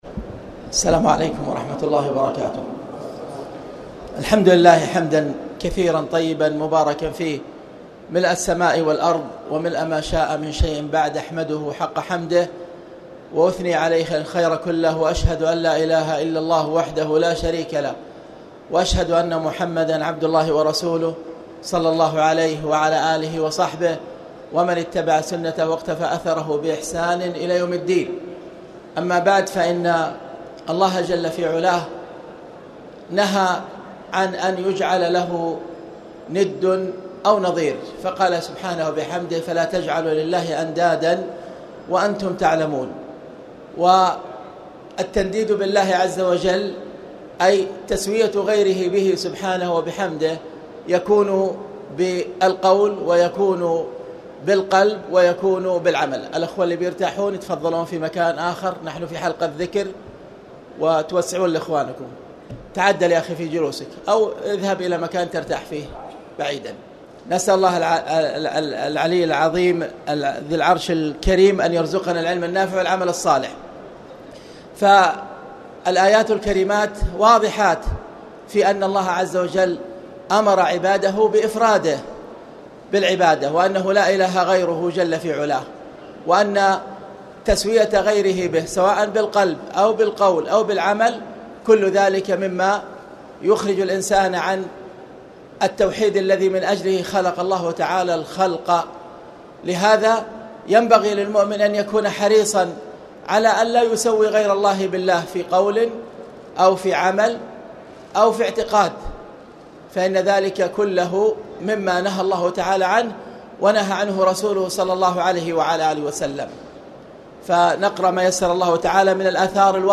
تاريخ النشر ١٥ رمضان ١٤٣٨ هـ المكان: المسجد الحرام الشيخ